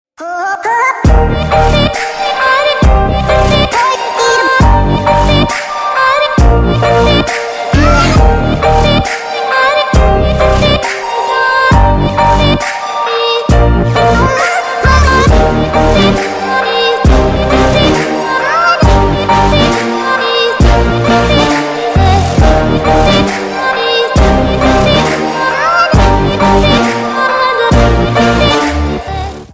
Рингтоны Ремиксы » # Рингтоны Электроника